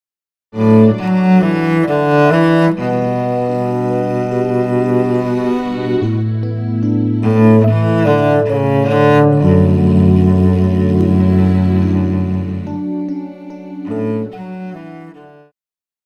Pop,Christian
Cello
Band
Instrumental
Ballad
Only backing